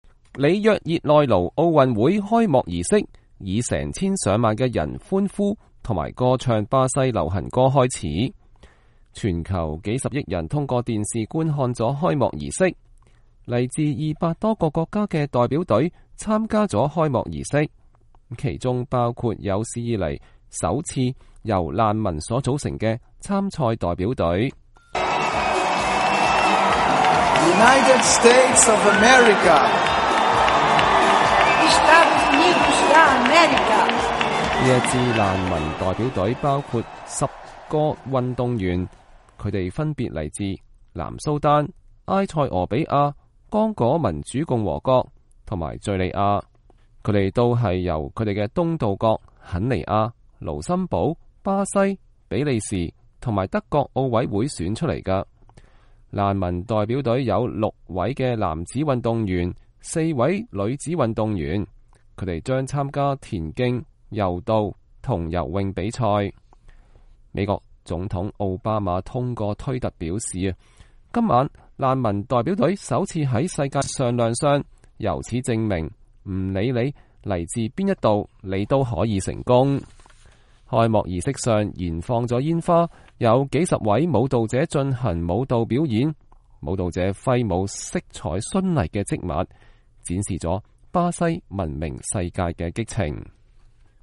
里約熱內盧奧運會開幕儀式以成千上萬的人歡呼和歌唱巴西的流行歌開始。